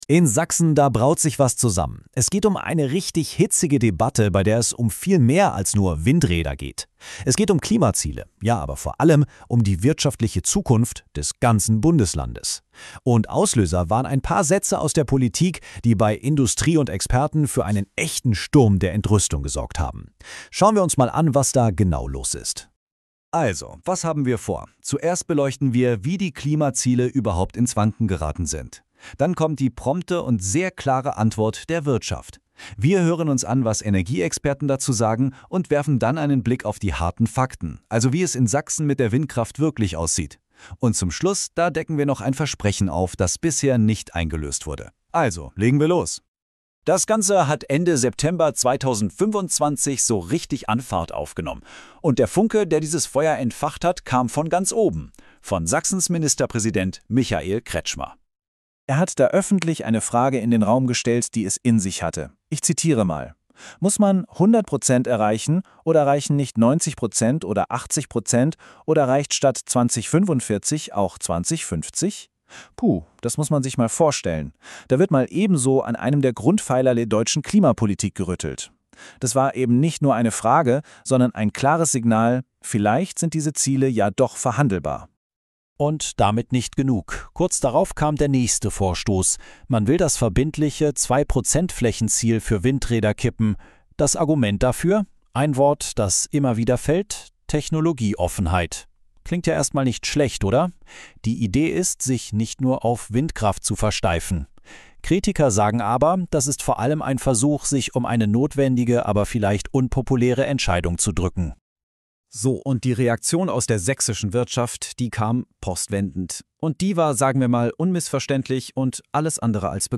diesem Kanal: Wir lassen Informationen von der KI aufbereiten und